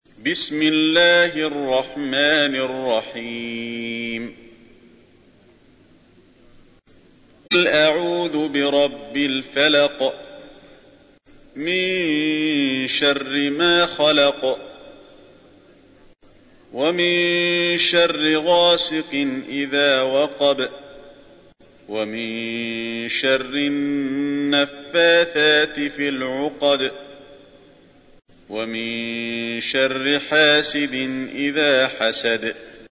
تحميل : 113. سورة الفلق / القارئ علي الحذيفي / القرآن الكريم / موقع يا حسين